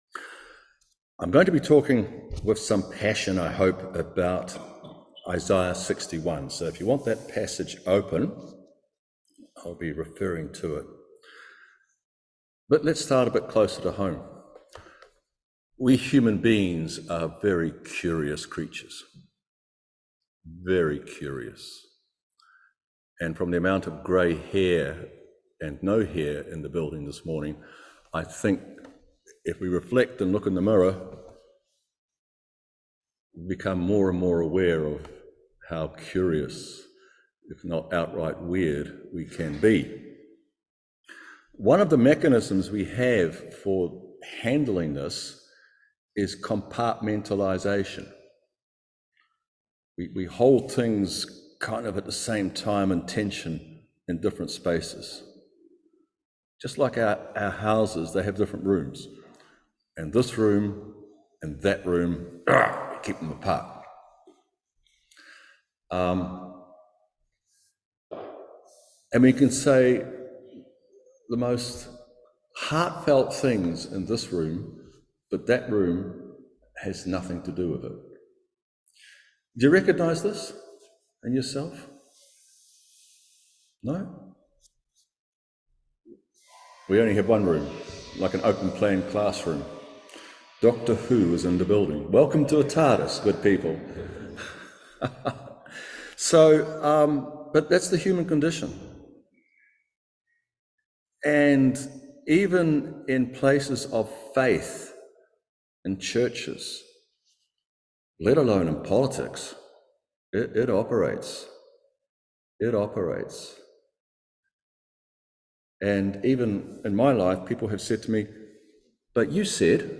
Service Type: Holy Communion